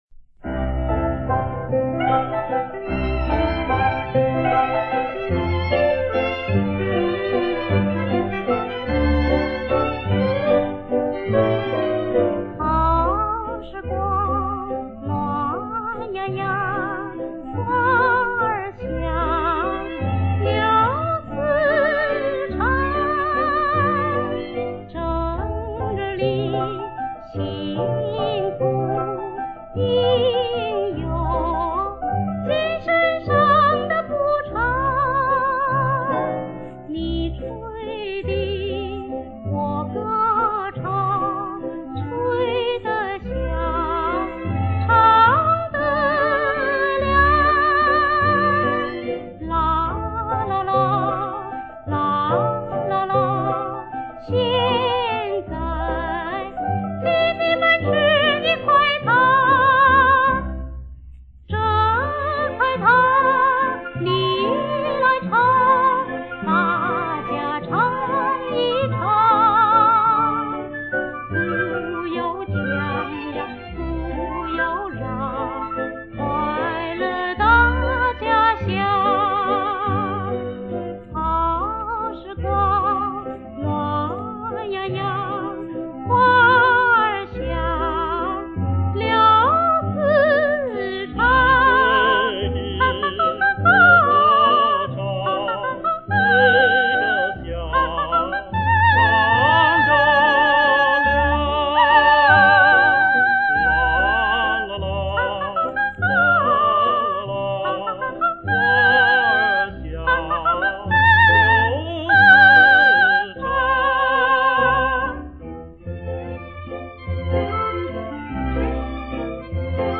国语时代曲系列
应考虑到歌曲都是几十年前的录音，调整一下自己的“金耳朵”，希望乐